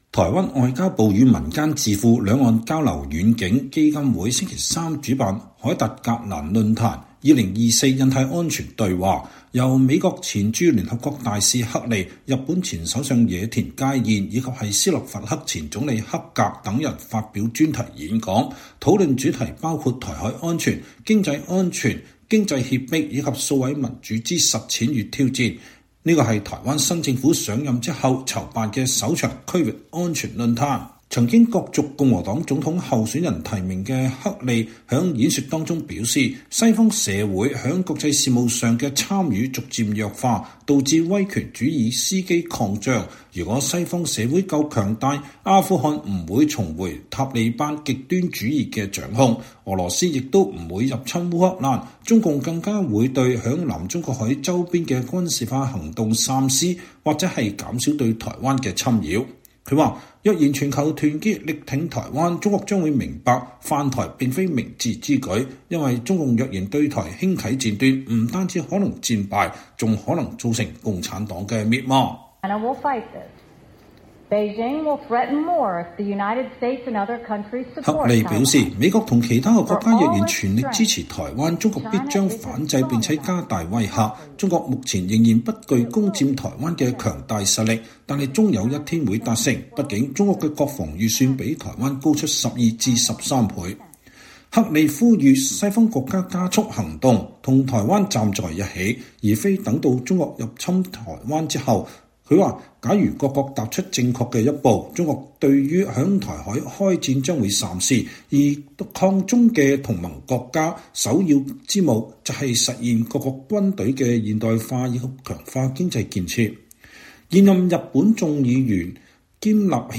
美國前常駐聯合國代表妮基‧黑利(Nikki Haley）在台北舉辦的《凱達格蘭論壇：2024印太安全對話》上發表演說。